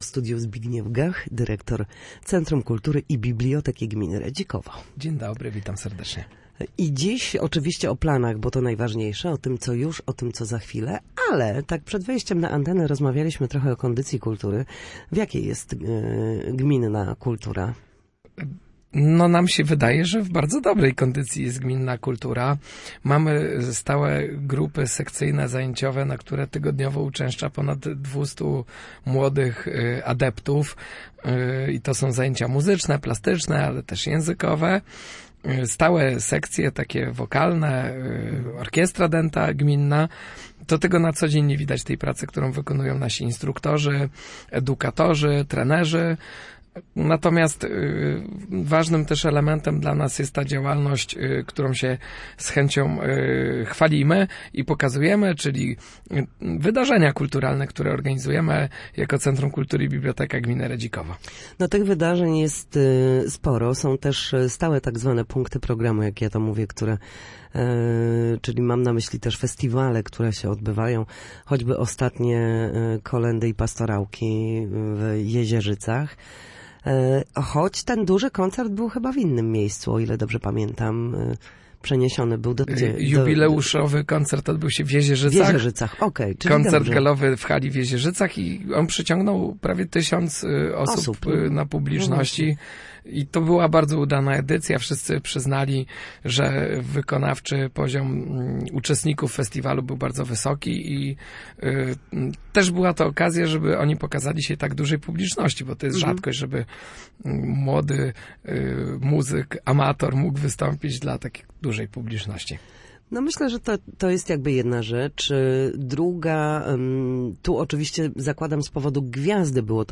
Na naszej antenie mówił o działaniach instytucji oraz wydarzeniach kulturalnych zaplanowanych na najbliższe miesiące.